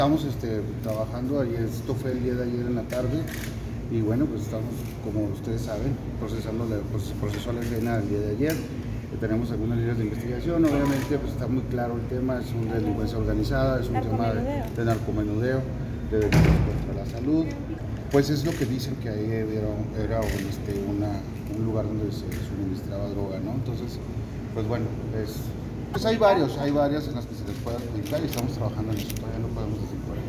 Fiscal general Roberto Fierro Duarte.